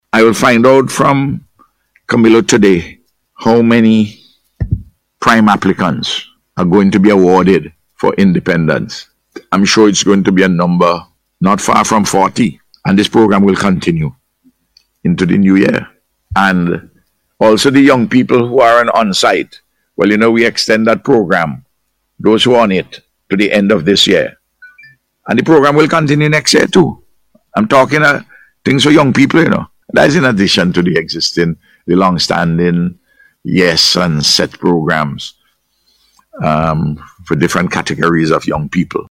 Prime Minister Dr Ralph Gonsalves made the announcement yesterday on the Face to Face program on NBC Radio, while giving an update on youth development programs across the nation.